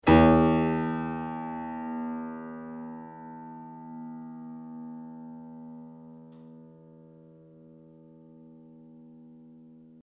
I campioni sonori che seguono sono stati registrati subito dopo il montaggio dei martelli (originali e replicati), sulle rispettive meccaniche (ottobre 2002): il suono dei martelli originali (nei quali le pelli sono solcate e un po' consunte alla sommità), è come facilmente prevedibile leggermente più pungente, di quello dei martelli replicati, al momento della registrazione intatti e del tutto privi di solchi.
Ascolta MI 1 (martello replicato), quarto livello di sollecitazione meccanica